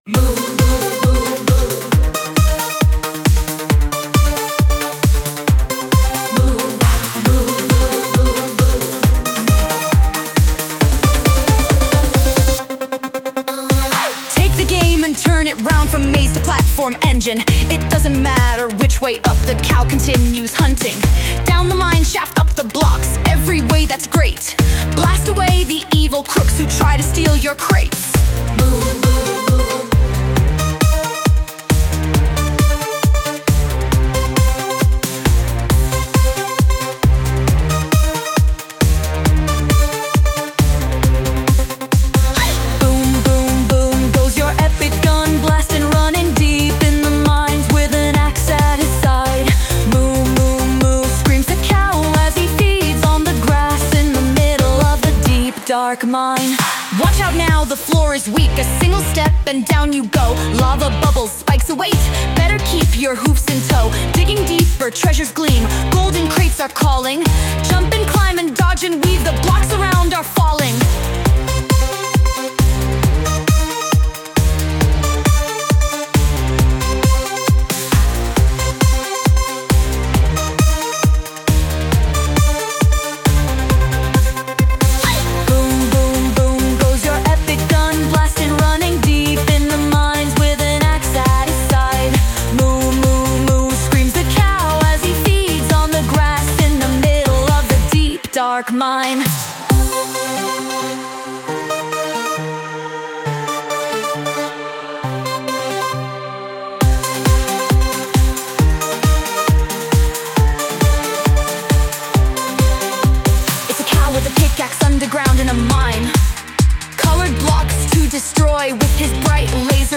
Lyrics : Half me, Half ChatGPT
Sung by Suno